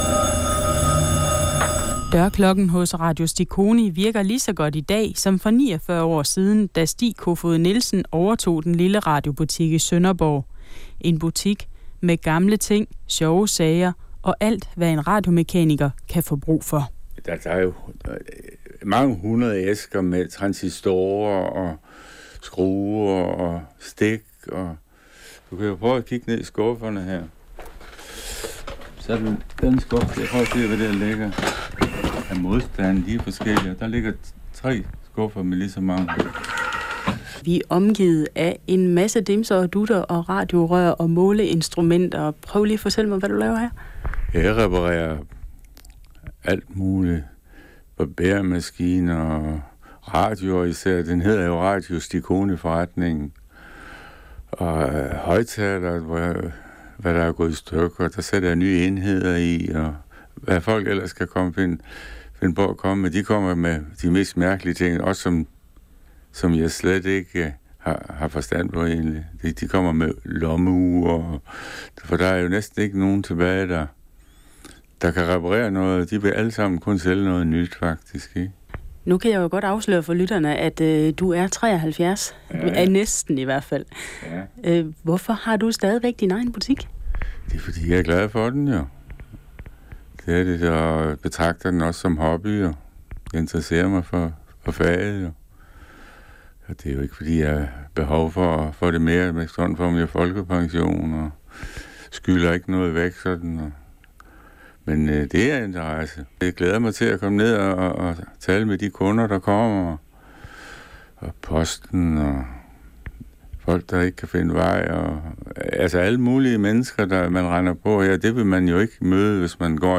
Sendt på Radio Syd den 13. september 2005 kl. 09:15.
Radio Syd interview med Radio Stikoni